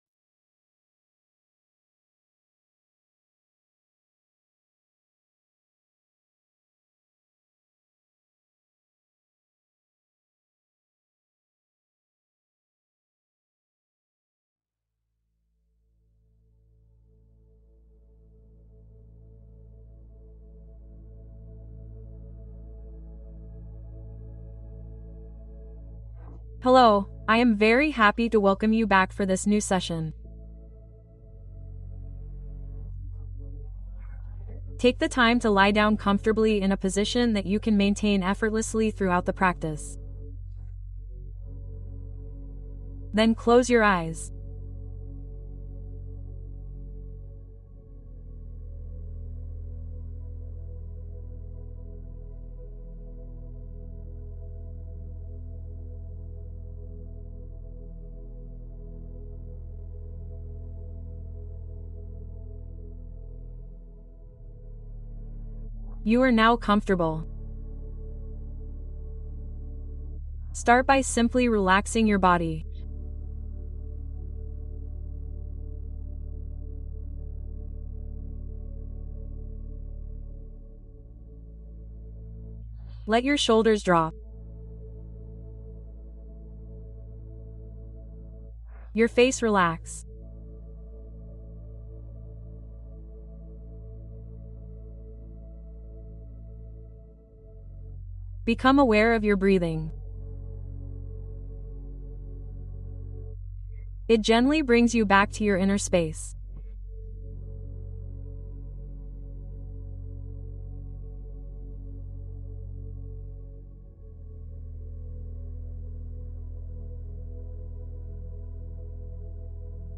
Équilibre Digestif : Méditation pour apaiser le ventre et l'esprit